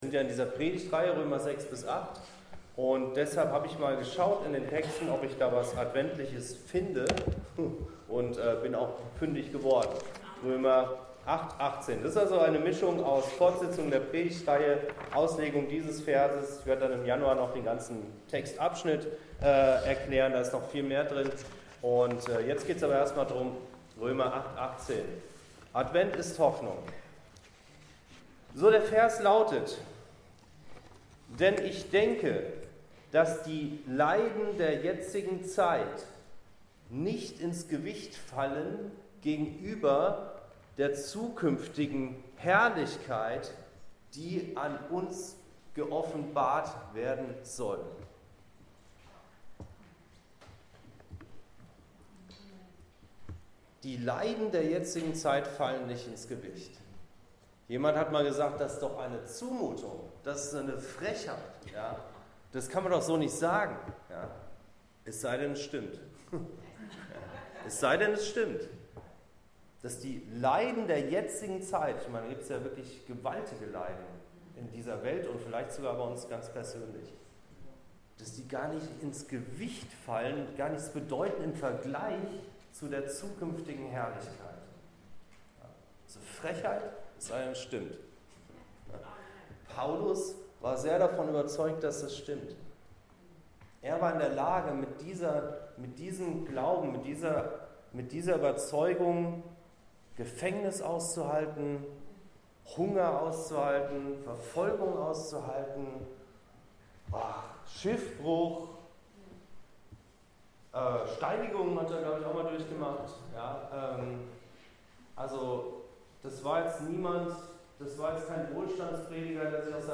Advent ist Hoffnung ~ Anskar-Kirche Hamburg- Predigten Podcast